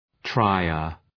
trier.mp3